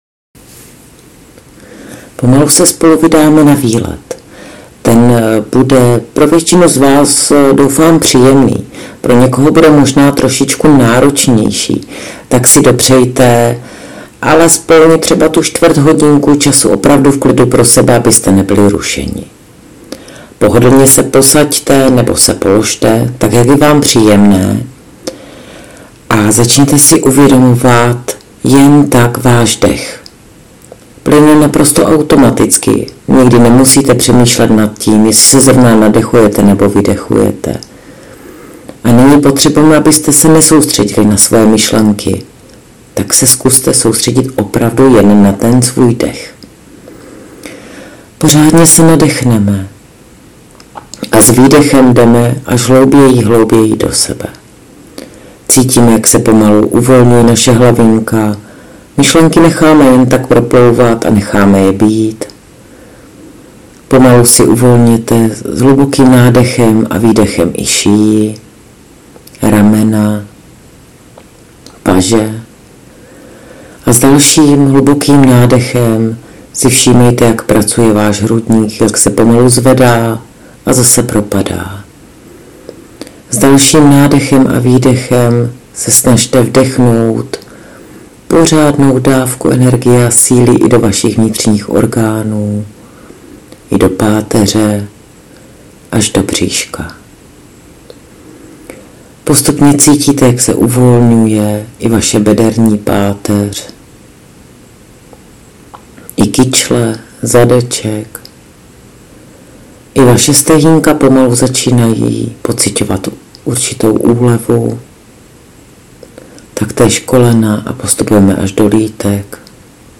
Nejprve si vysvětlíme, co to je a proč bychom své místo na těle měli znát. Ve druhém videu (spíš audiu) najdete konkrétní vizualizaci.